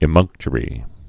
(ĭ-mŭngktə-rē)